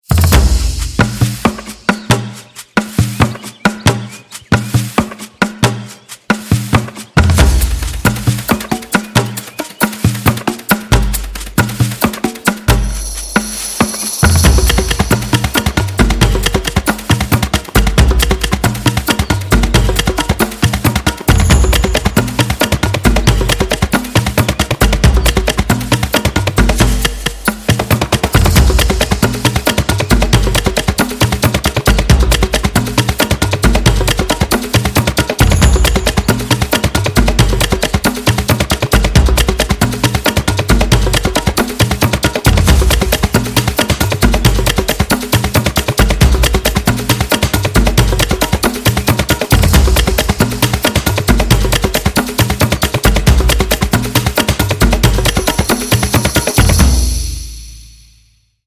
Percussion World